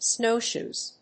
/ˈsnoˌʃuz(米国英語), ˈsnəʊˌʃu:z(英国英語)/